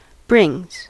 Ääntäminen
Ääntäminen US Tuntematon aksentti: IPA : /ˈbɹɪŋz/ Haettu sana löytyi näillä lähdekielillä: englanti Käännöksiä ei löytynyt valitulle kohdekielelle.